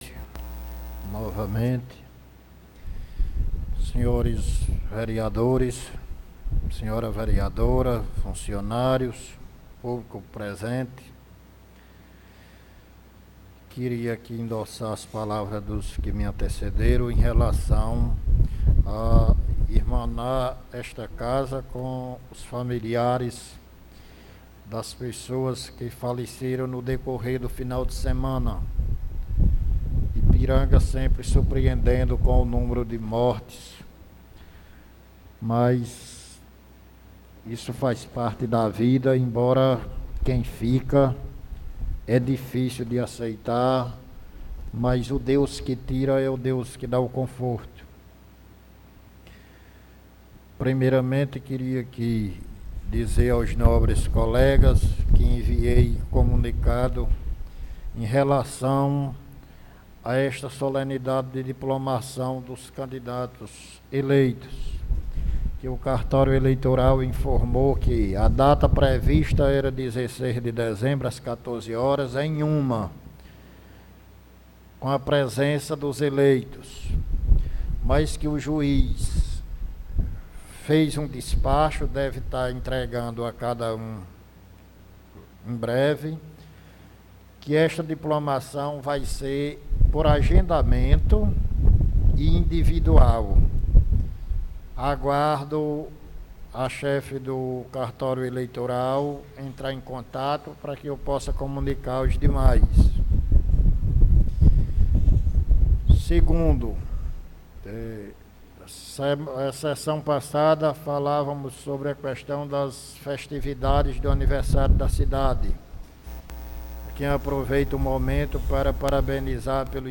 Pronunciamento Ver João Batista
2ª Sessão Ordinária